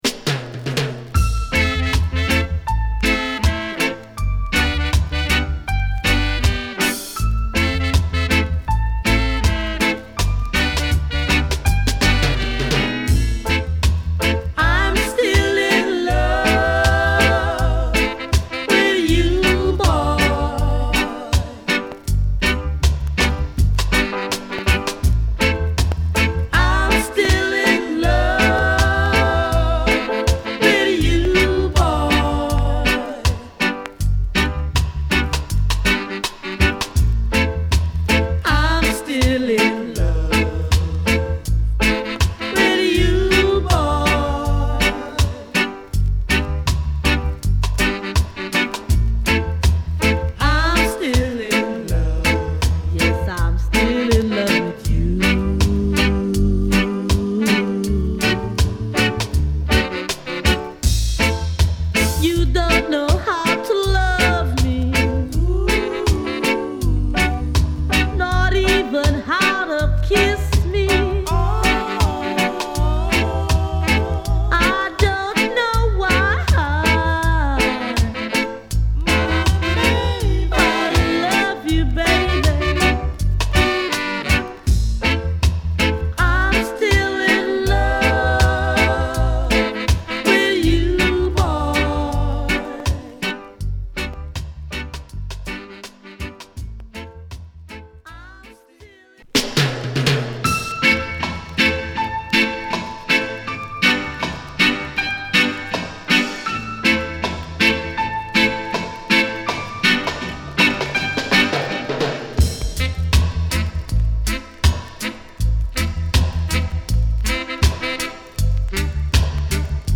Jamaica
柔らかなラヴァーズ・ヴァージョンに仕上がったナイスカヴァーです。